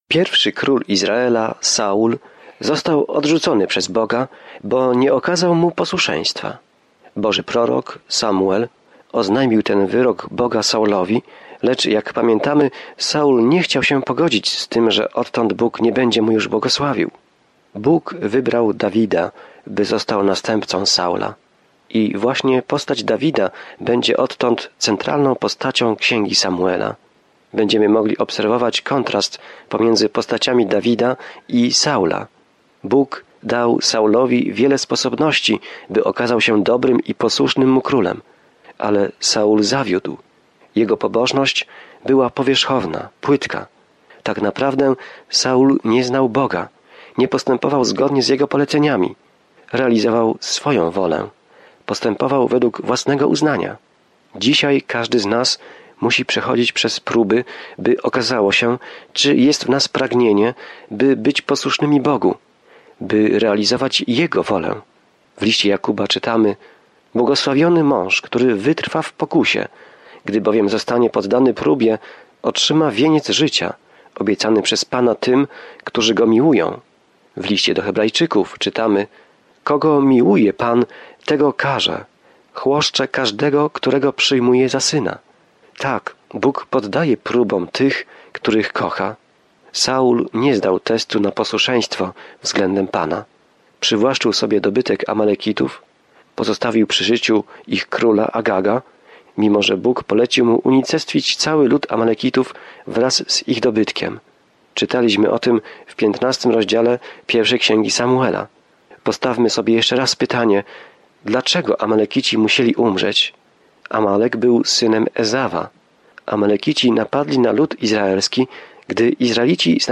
Codziennie podróżuj przez 1. Samuela, słuchając studium audio i czytając wybrane wersety ze słowa Bożego.